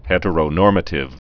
(hĕtə-rō-nôrmə-tĭv)